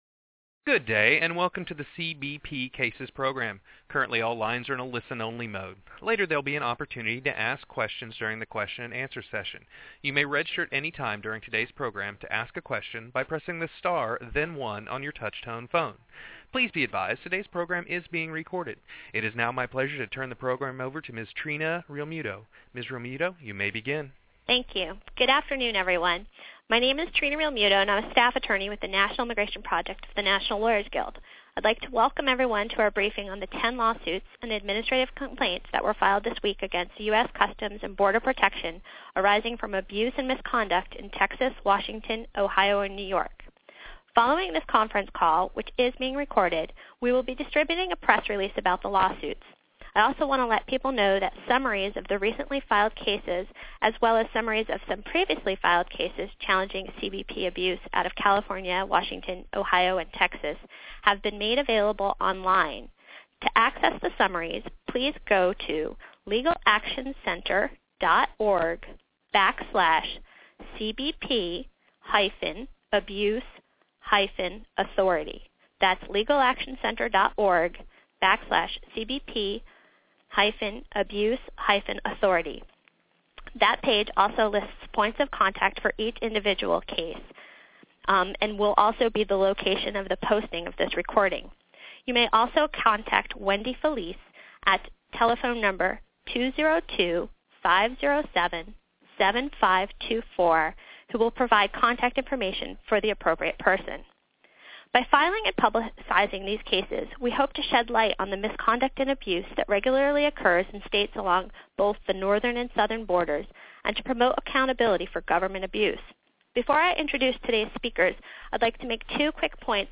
Click here [1] to view the press release or here [2] to listen to a tele-briefing on the cases from March 13, 2013.